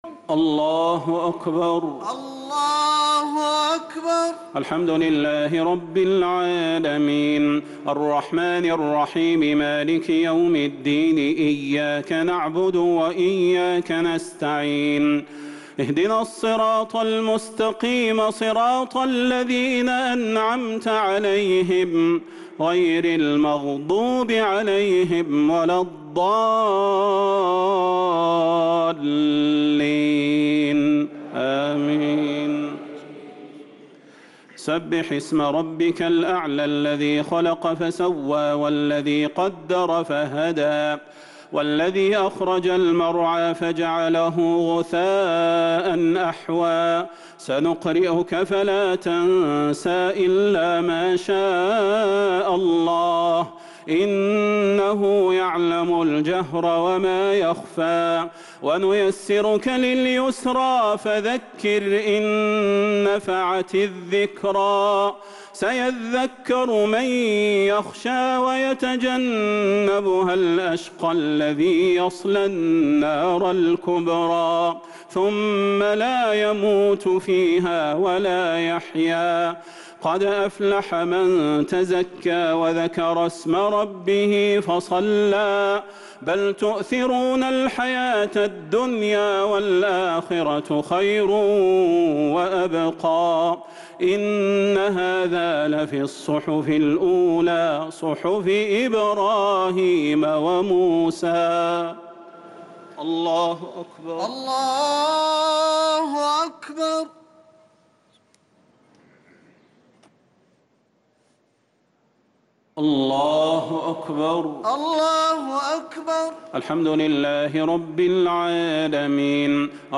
الشفع و الوتر ليلة 14 رمضان 1444هـ | Witr 14 st night Ramadan 1444H > تراويح الحرم النبوي عام 1444 🕌 > التراويح - تلاوات الحرمين